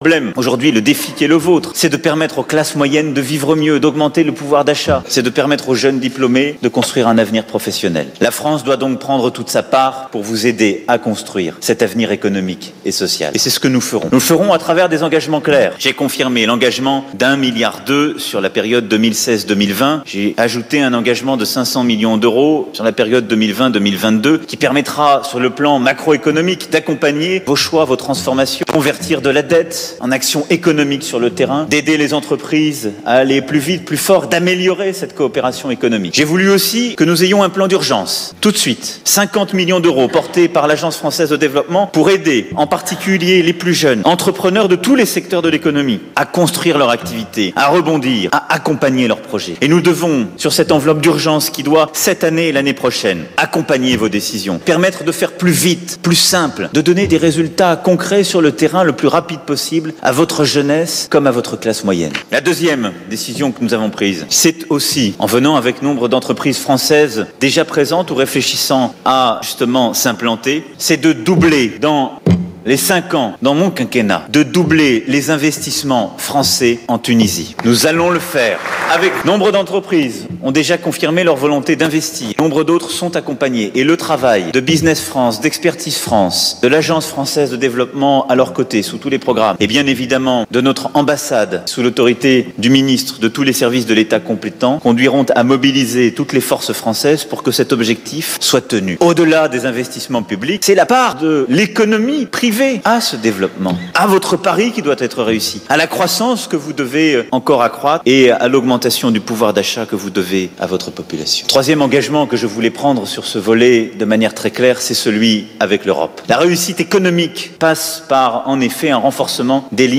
أعلن الرئيس الفرنسي ايمانويل ماكرون خلال كلمة توجه بها اليوم الخميس إلى الشعب التونسي من منبر مجلس نواب الشعل، أن حكومة بلده وضعت خطة لمضاعفة الاستثمارات الفرنسية في تونس، ستتعهد بها خلال مخطط خماسي للاستثمارات بالقطاع العام.